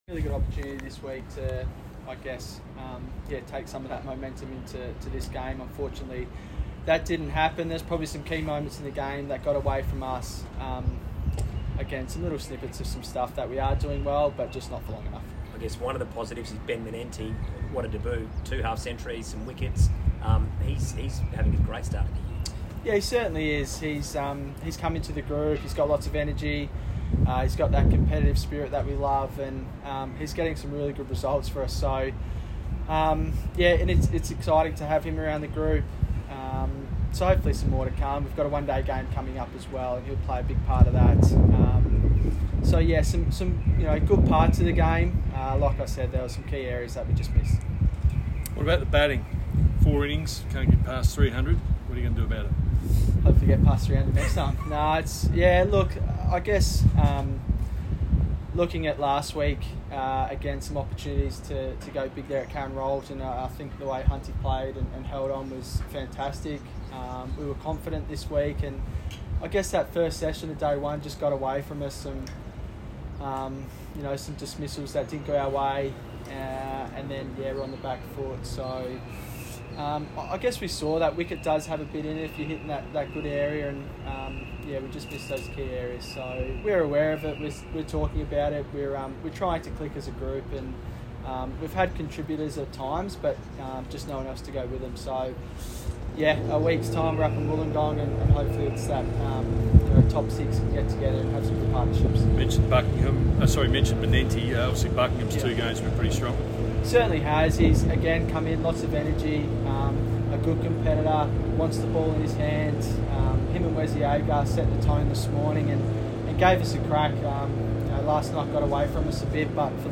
Alex Carey speaks following Marsh Sheffield Shield Day Four: West End Redbacks v Tasmania